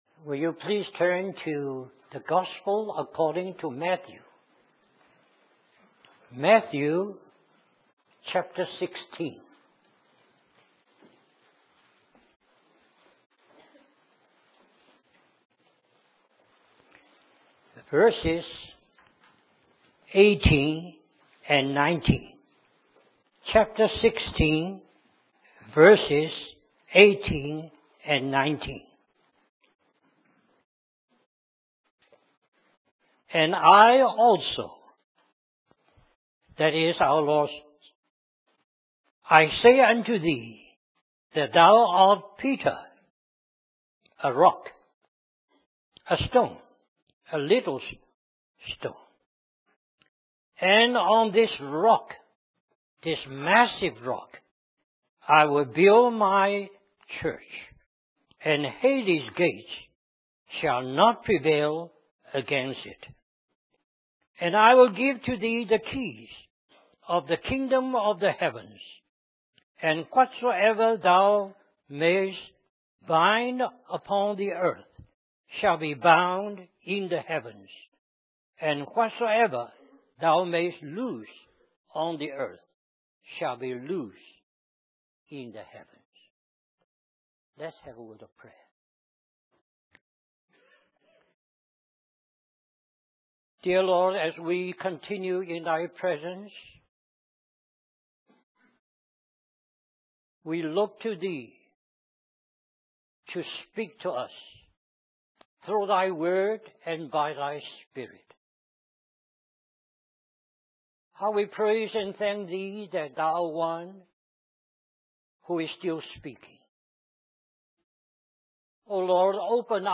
In this sermon, the preacher emphasizes the importance of discipleship and the Great Commission. He explains that discipleship is not about making followers of ourselves, but rather leading people to Christ and helping them become transformed in His likeness.